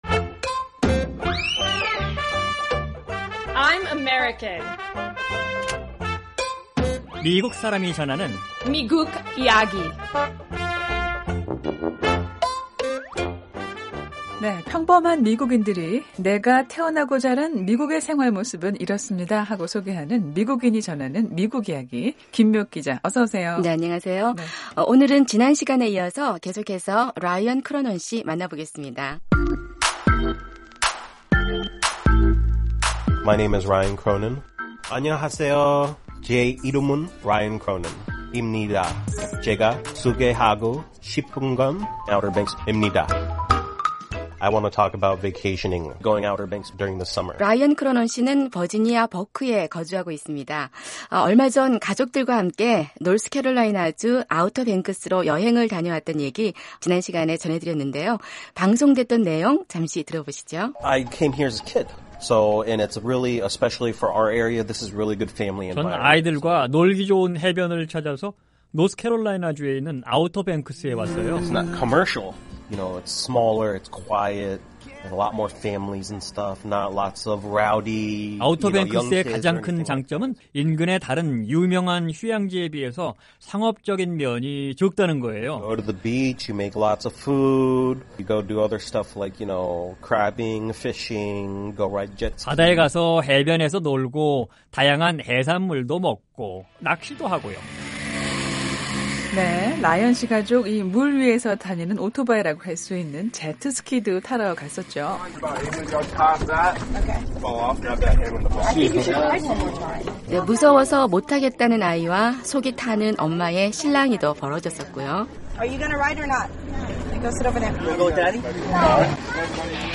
평범한 미국인이 전하는 미국 생활 이야기.